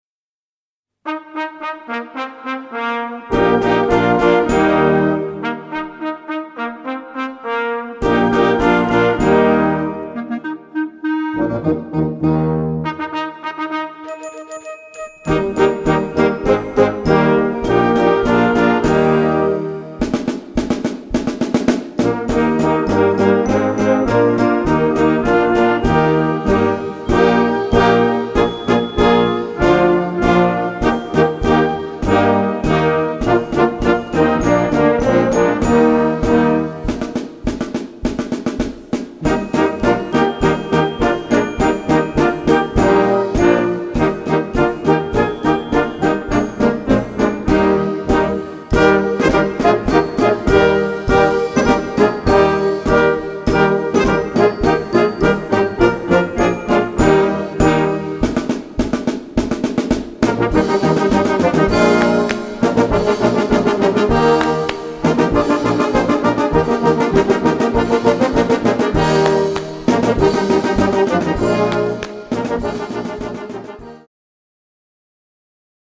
Gattung: Medley
2:16 Minuten Besetzung: Blasorchester Zu hören auf